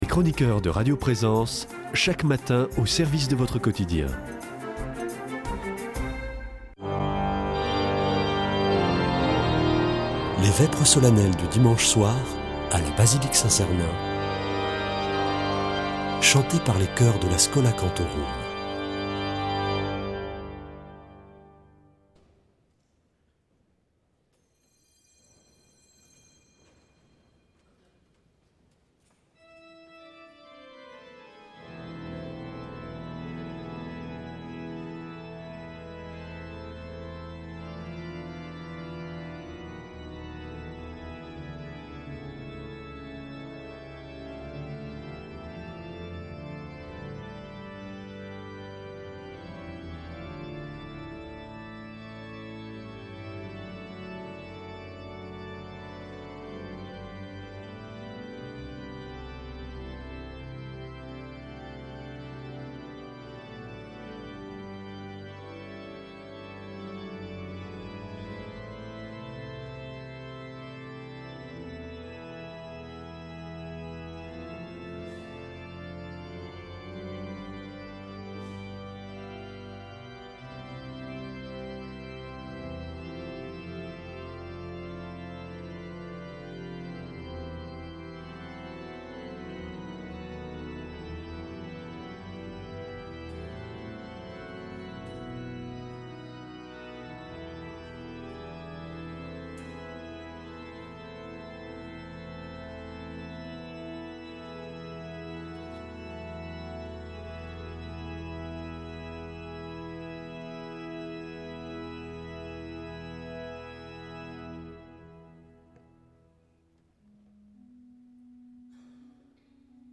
Vêpres de Saint Sernin du 06 oct.
Une émission présentée par Schola Saint Sernin Chanteurs